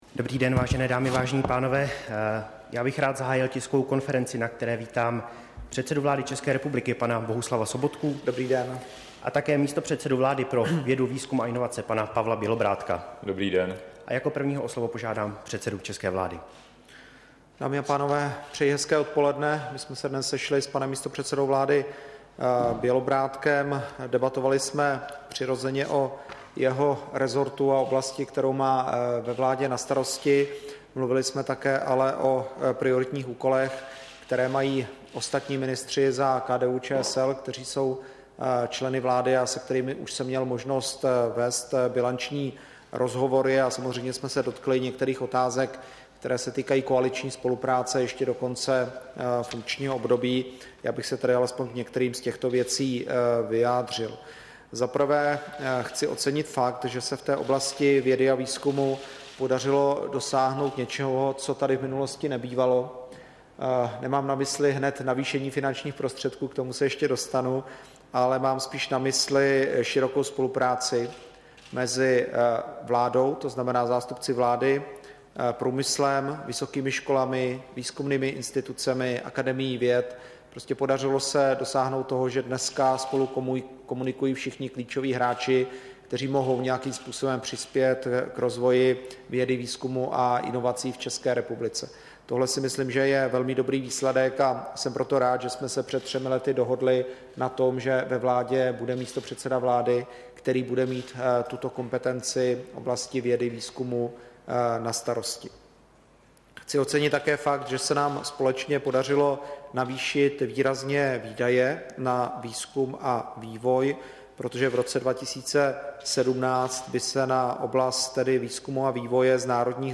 Tisková konference po jednání předsedy vlády Sobotky s místopředsedou vlády pro vědu, výzkum a inovace, 2. listopadu 2016